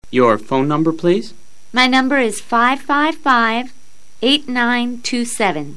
Escucha atentamente las cuatro conversaciones (a, b, c y d). ¿Son CORRECTOS o INCORRECTOS los números telefónicos?